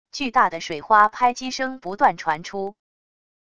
巨大的水花拍击声不断传出wav音频